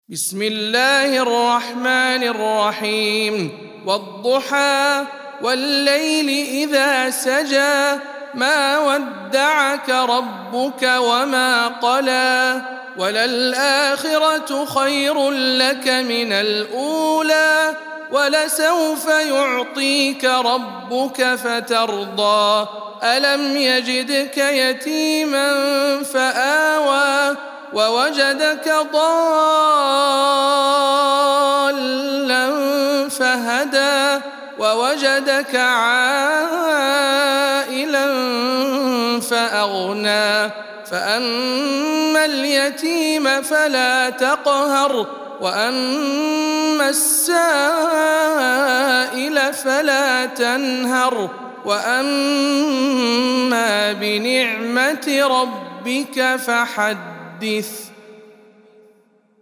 سورة الضحى - رواية ابن وردان عن أبي جعفر